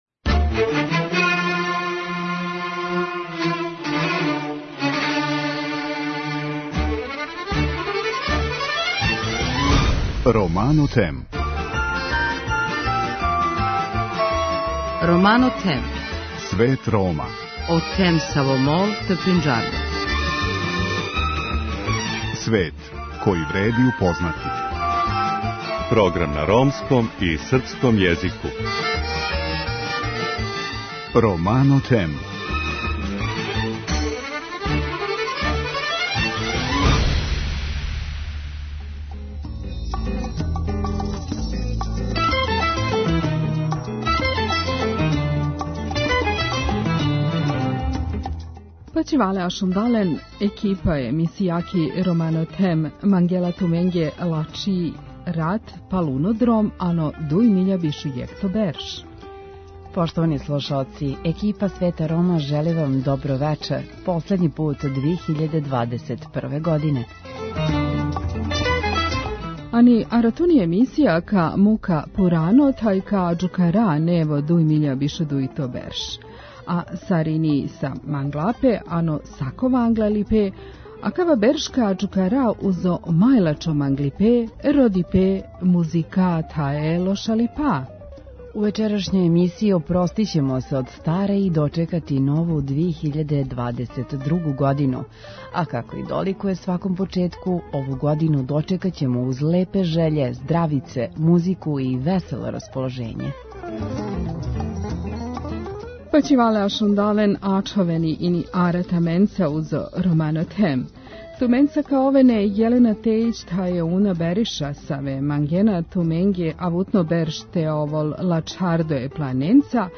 Здравице, добра музика и весело расположење се подразумевају!